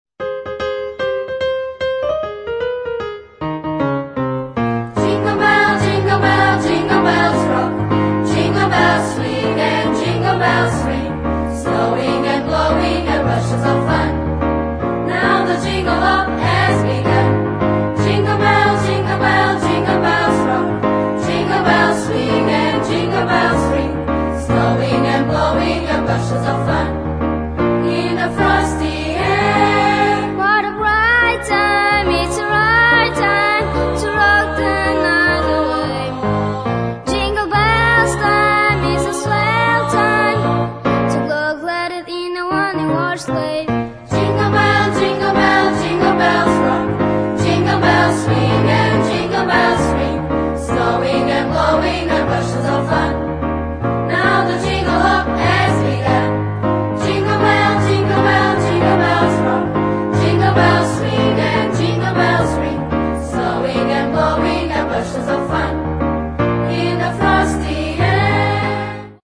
CD086 – CORAL JUVENIL DO ORFEÃO DE RIO TINTO